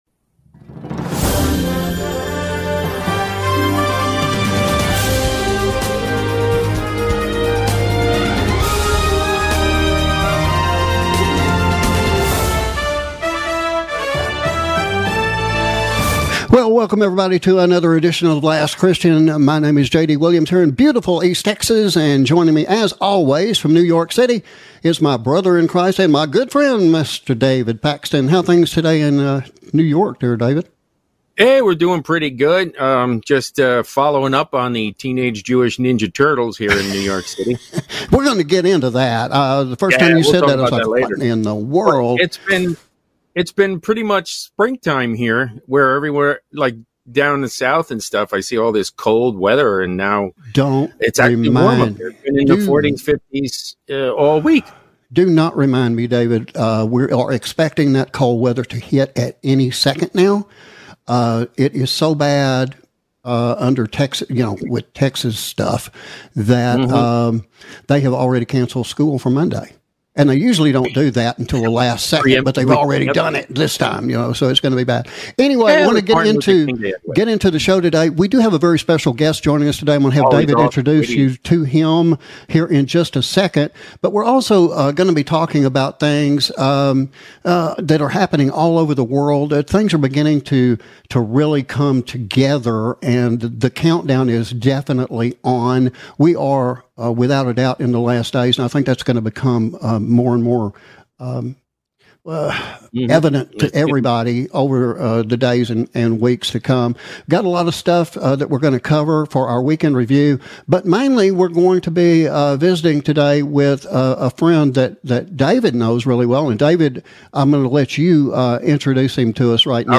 as we open an in-depth conversation concerning AI. Better known as Artificial Intelligence, and how it impacts each of us. Also included is our recap of World events, we call, the Week in Review.